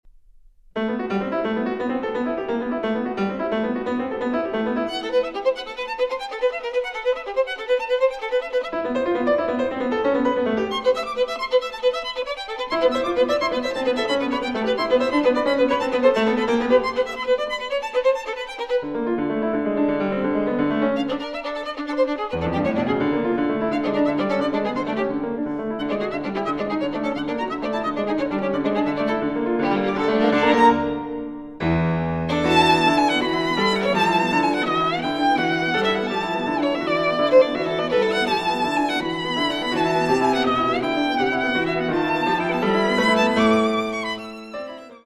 Tarantella (4:01)